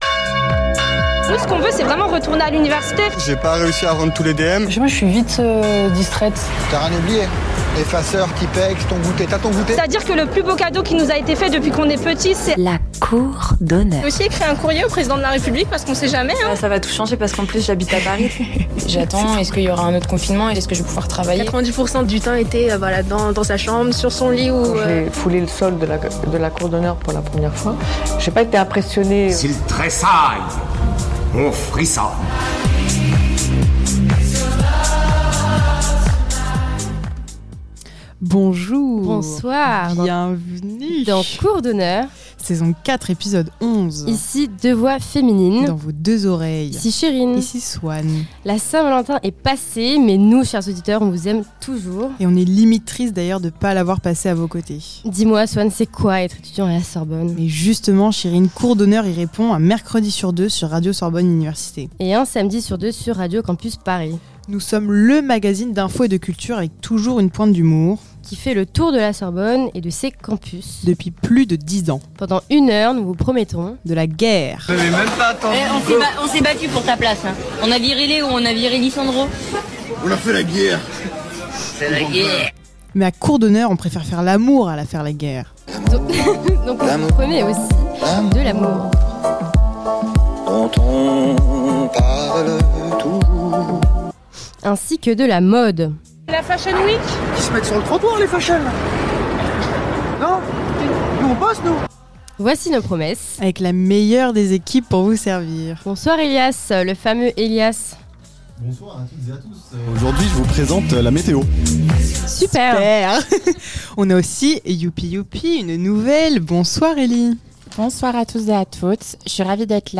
Type Magazine Culture